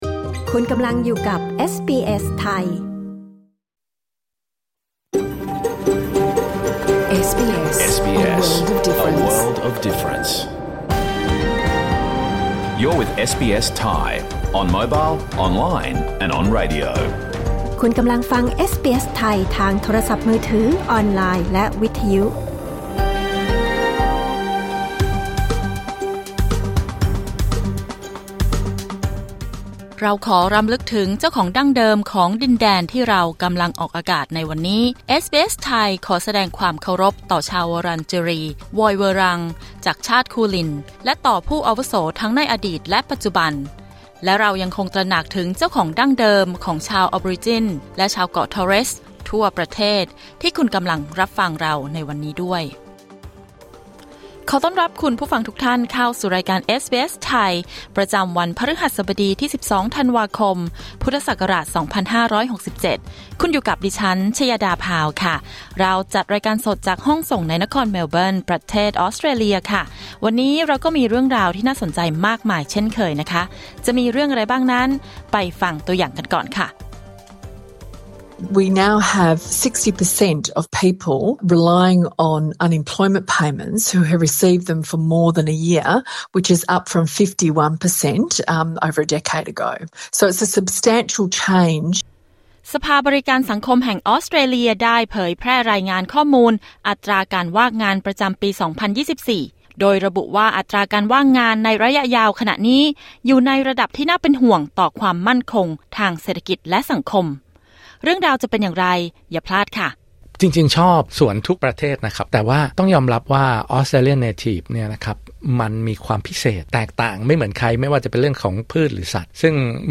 รายการสด 12 ธันวาคม 2567